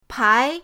pai2.mp3